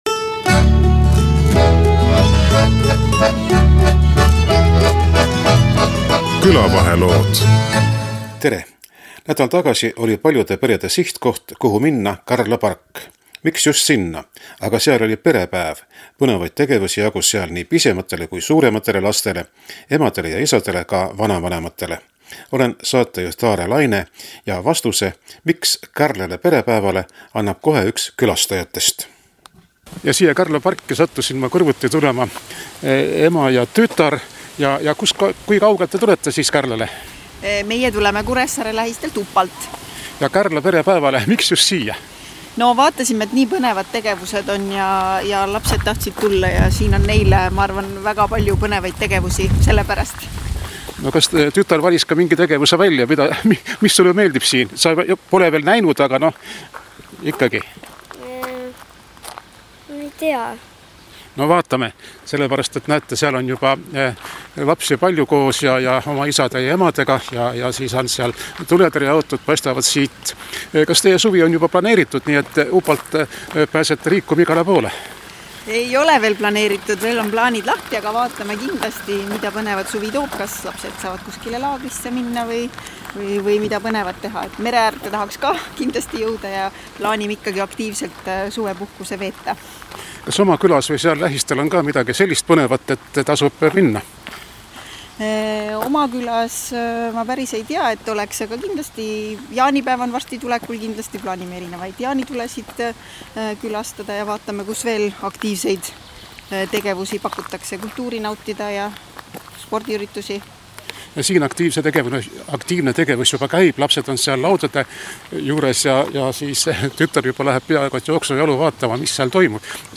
Kärla pargis toimuvale perepäevale tulid isad ja emad oma võsukestega, ka vanavanemad, 7. juuni ennelõunal nii lähemalt kui kaugemalt.
Päeva korraldajad räägivad, mis on selle sündmuse eesmärk, miks see vajalik on.